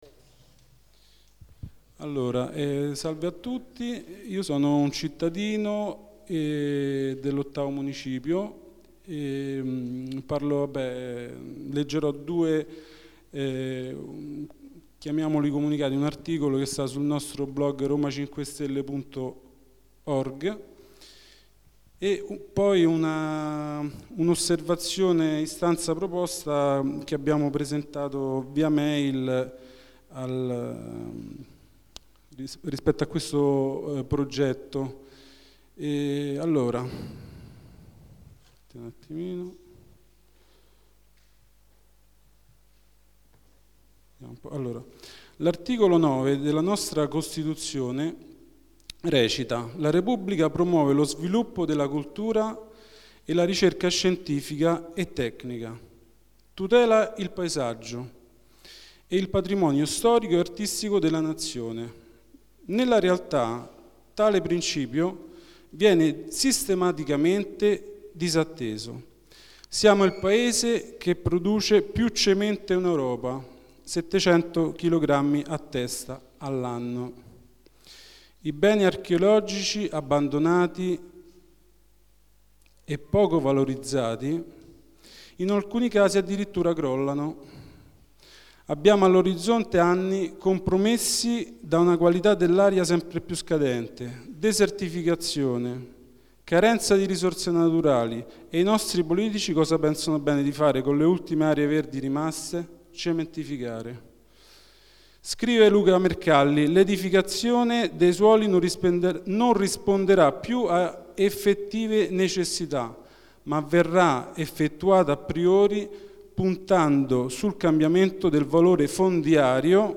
Assemblea Partecipativa TBMRegistrazione integrale dell'incontro svoltosi il 13 settembre 2012 presso la sala consiliare del Municipio VIII in Via D. Cambellotti, 11.